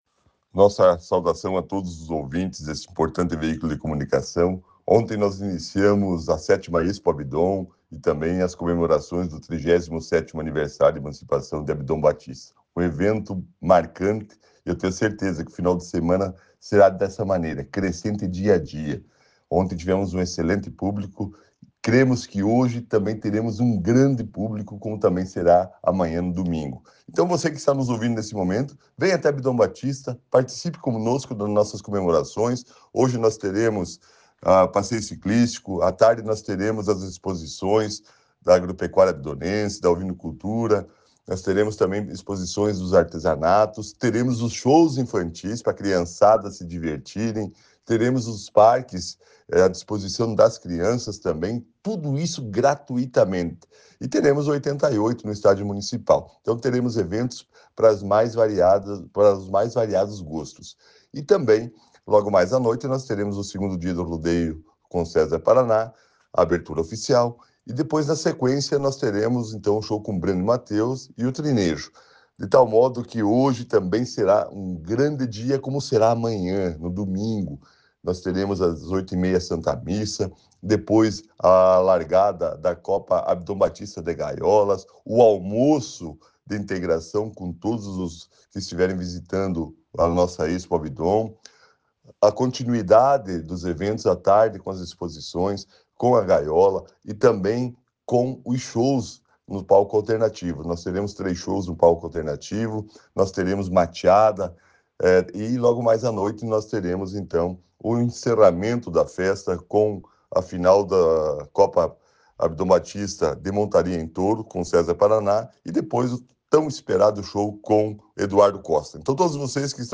O prefeito de Abdon Batista, Lucimar Salmória, destacou a importância do evento para o município e reforçou o convite à população.